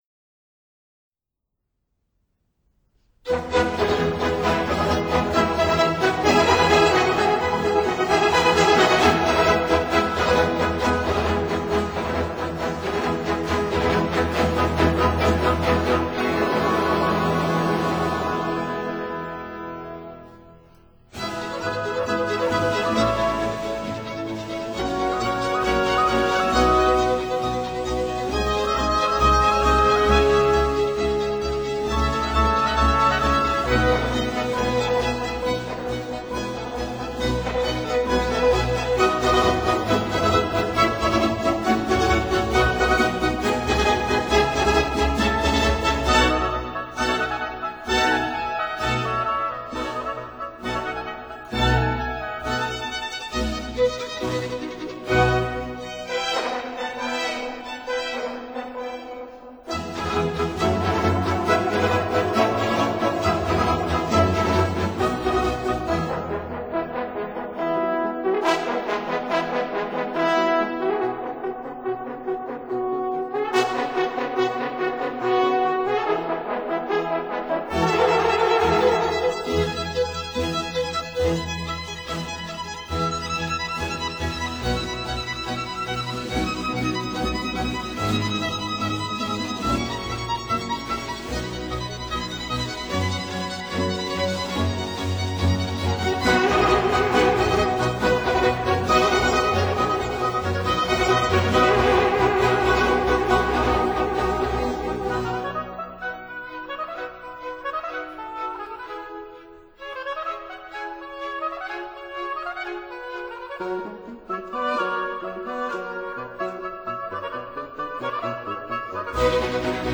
所屬時期/樂派： 巴洛克威尼斯樂派
Concerto for Violin, 2 Oboes, Bassoon & 2 Horns
(Period Instruments)